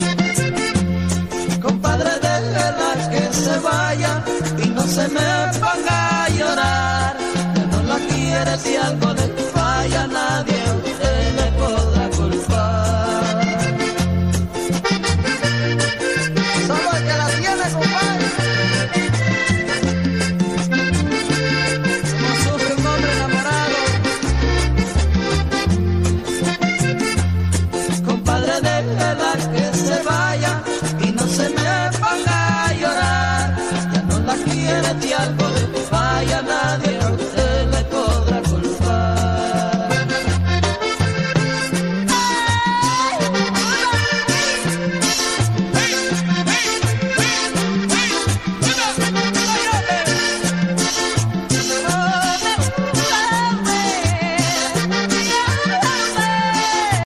musica típica panameña